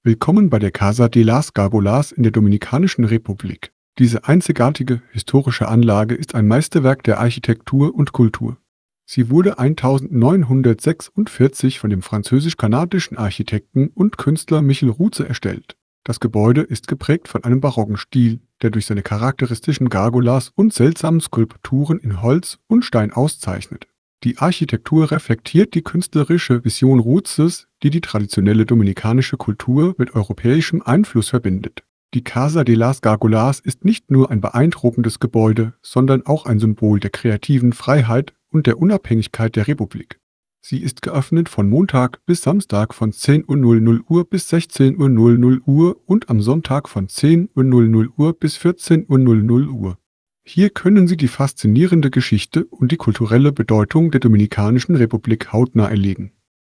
karibeo_api / tts / cache / 17ac63f3fae0627e063252ef38fb0cff.wav